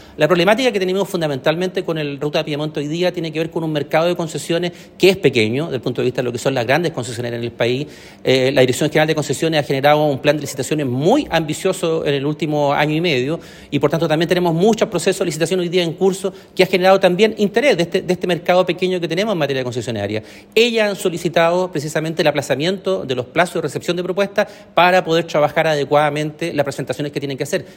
Sobre la situación actual del proyecto, el seremi de Obras Públicas, Hugo Cautivo, explicó a Radio Bío Bío que se está trabajando en la licitación y que las empresas interesadas han solicitado aplazar la recepción de propuestas.